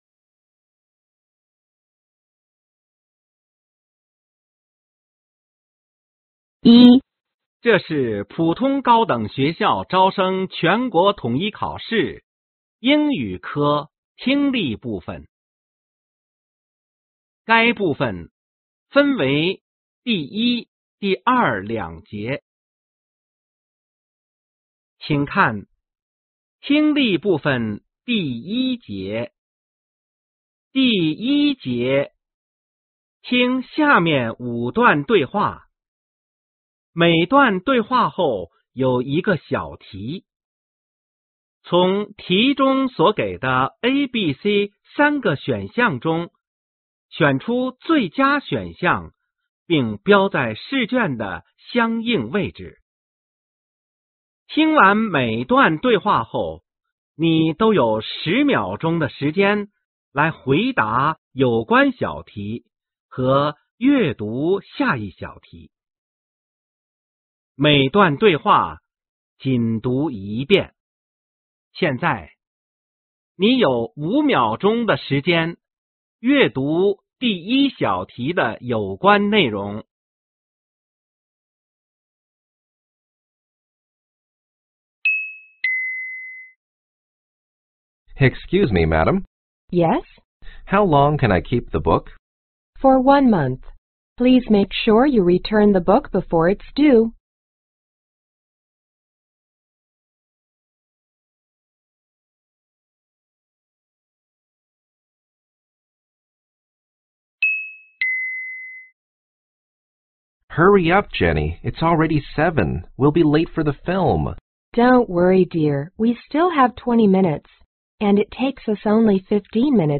听完每段对话后，你都有10秒钟的时间来回答有关小题和阅读下一小题。每段对话仅读一遍。